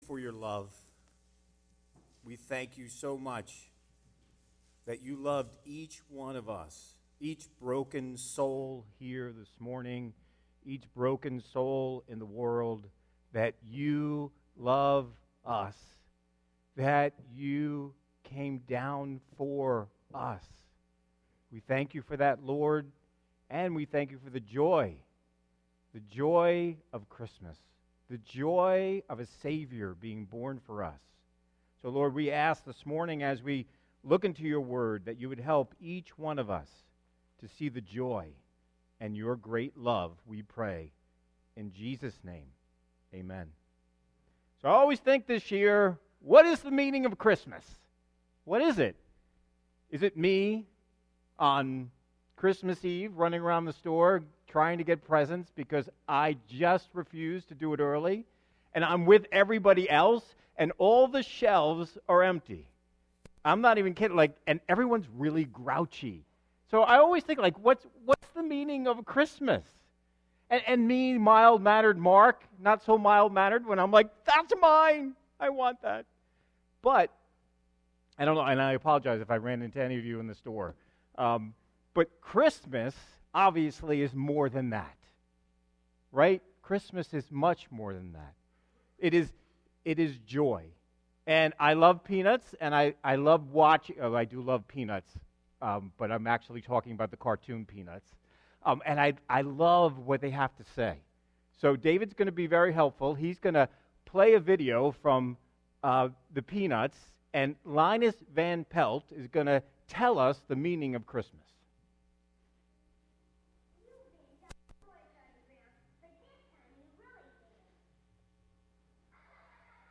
Sermons Archive - New Village Church